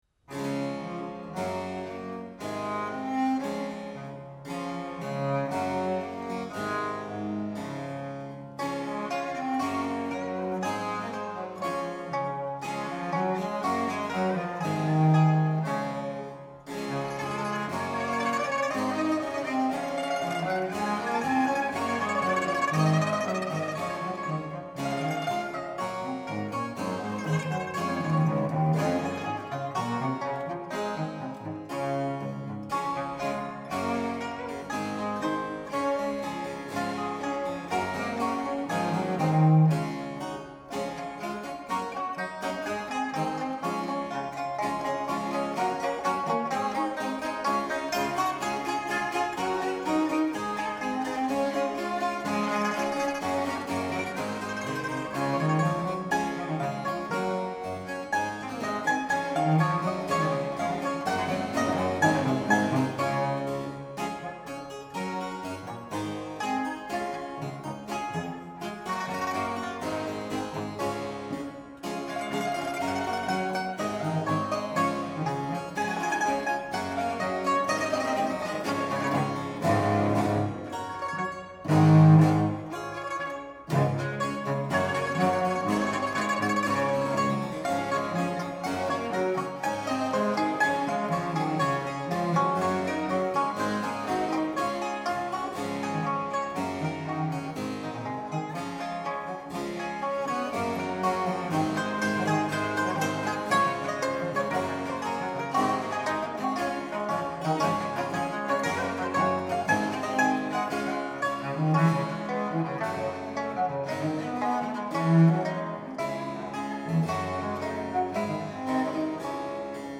"Innovative and energetic."
harpsichord
recorder
baroque violin
baroque cello